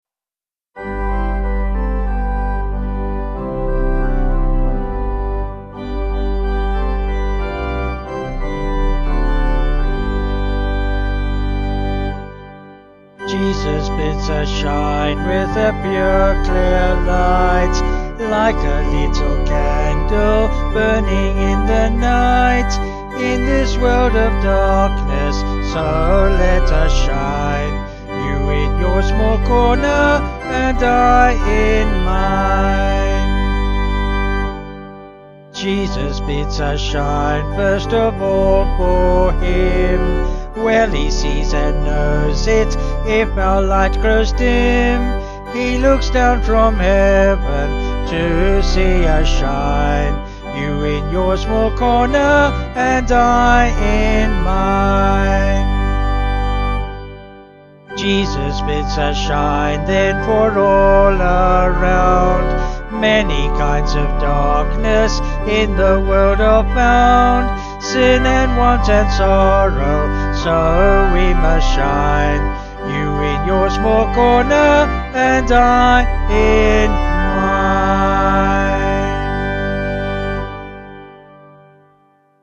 Vocals and Organ   192.2kb Sung Lyrics